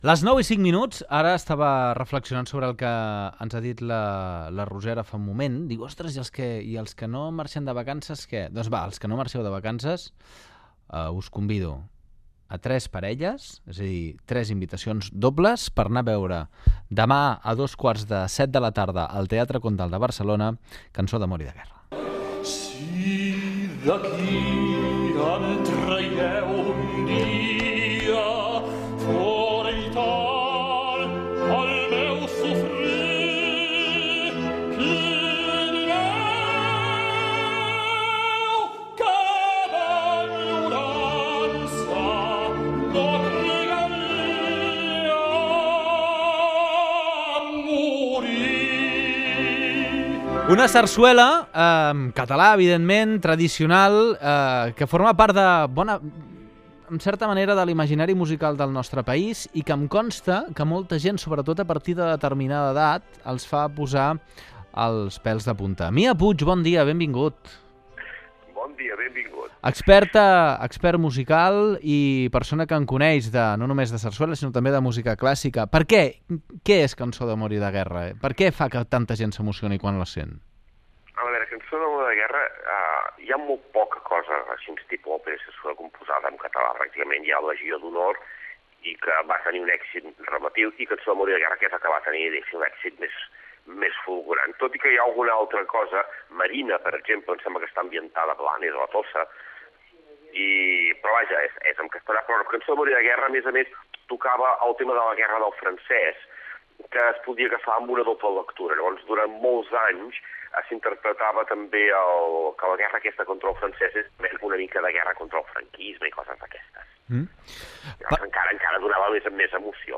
Entreteniment
Fragment extret de l'arxiu sonor de COM Ràdio.